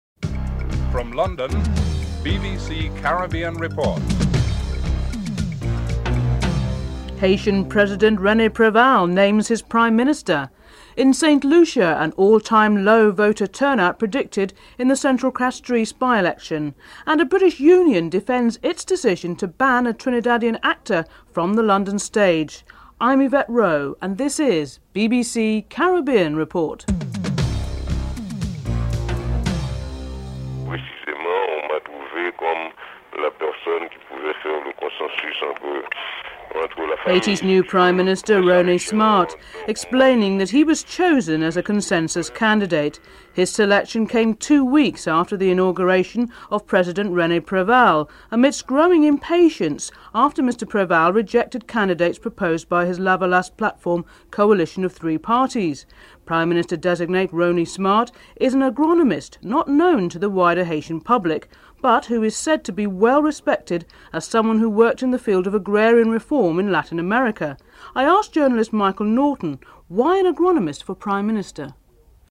1. Headlines (00:00-00:29)
5. A high level business and local government delegation is in Cuba with talks of trade and mutual assistance. Premier of Gauteng Gabirel 'Tokyo' Sexwale is interviewed (10:54-12:25)